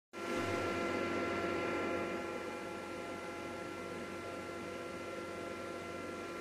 P910's full throttle fan noise six seconds of recording.
• Prudently, we will test the system in its initial stages of configuration by unleashing its "wanna-be an helicopter" character...
p910-full-throttle-fan-noise.m4a